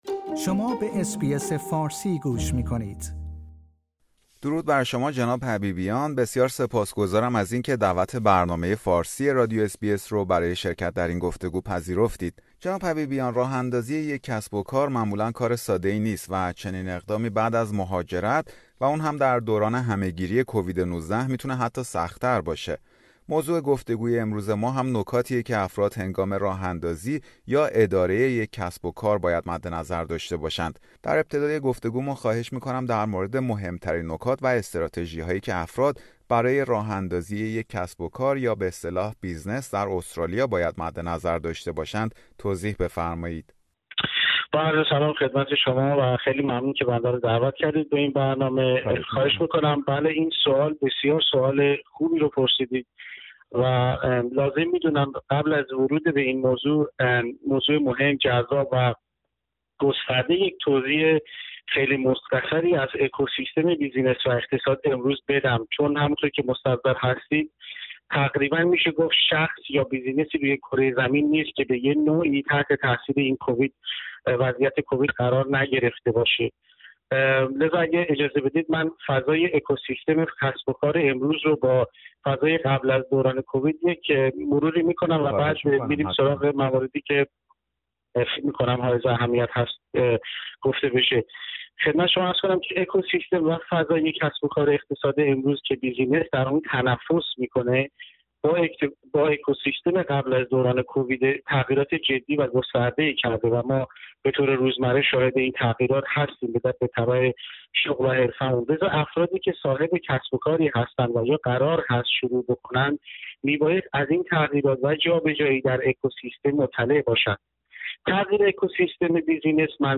گفتگو; قبل از خرید یا فروش یک بیزنس (کسب و کار) به این نکات و توصیه ها توجه کنید